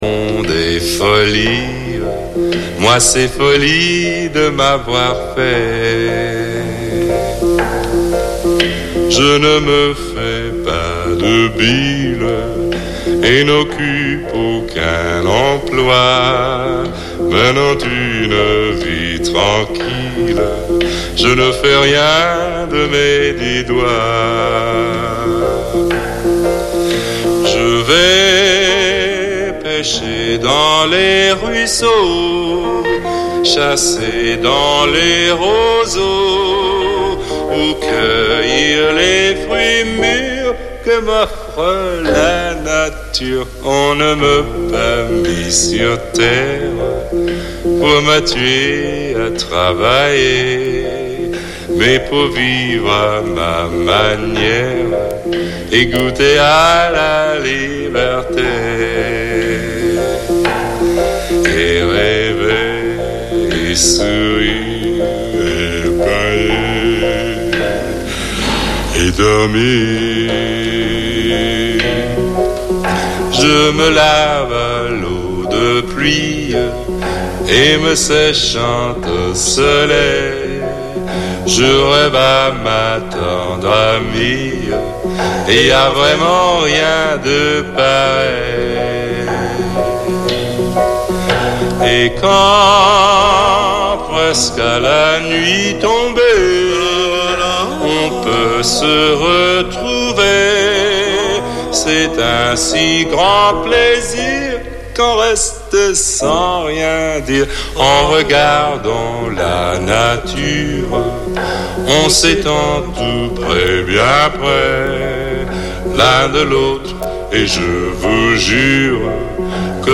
Débutez vos dimanches matin en musette !